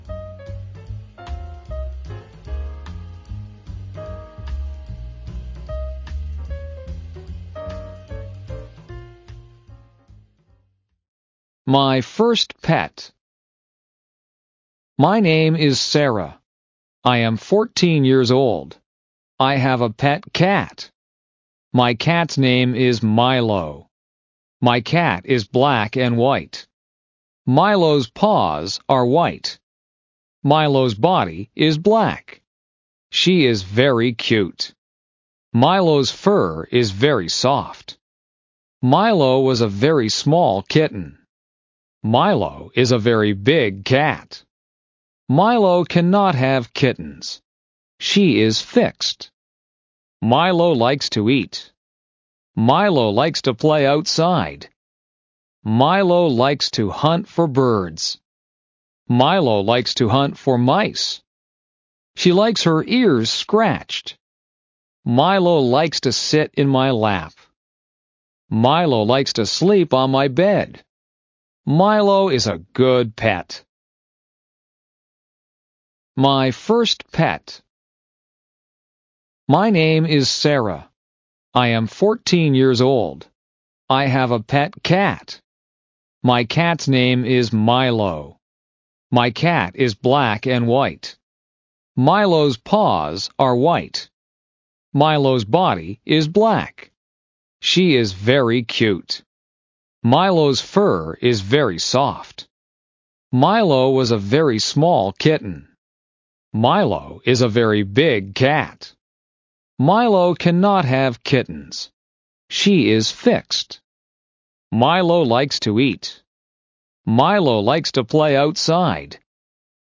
A selected English listening passage, 'My First Pet', tells the warm story of 14-year-old Sarah and her cat Milo. Perfect for beginners to practice listening and vocabulary.